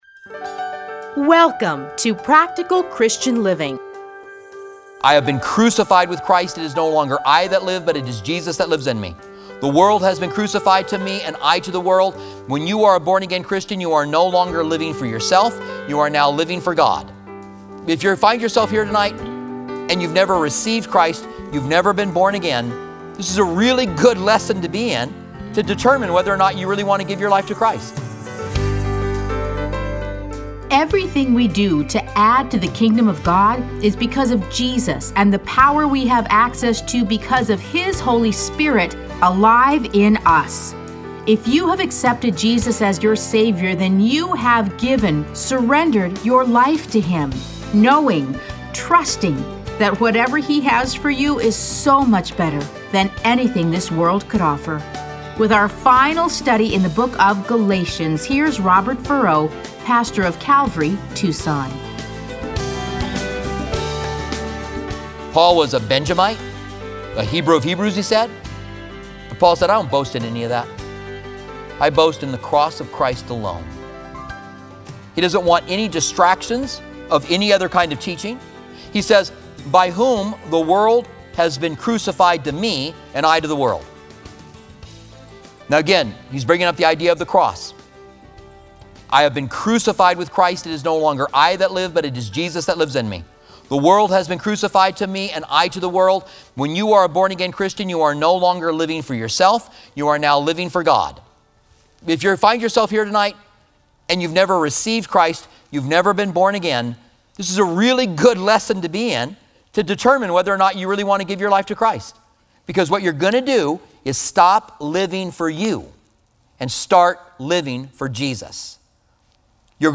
Listen to a teaching from Galatians 6:11-18.